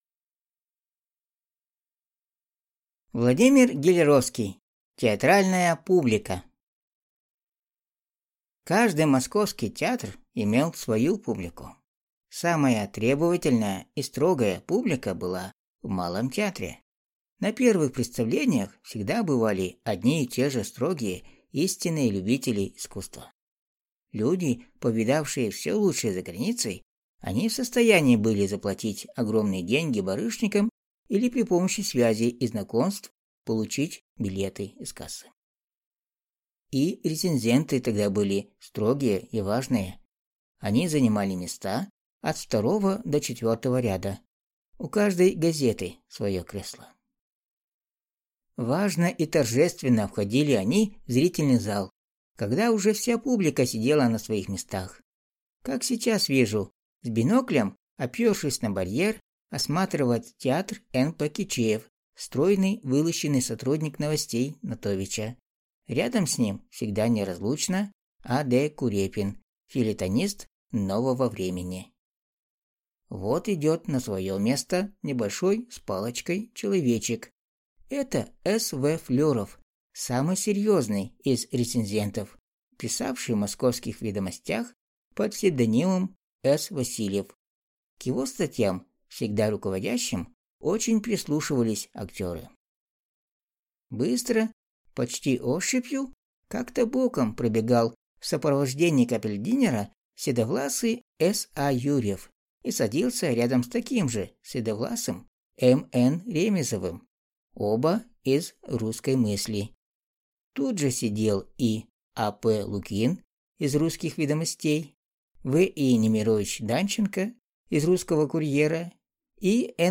Аудиокнига Театральная публика | Библиотека аудиокниг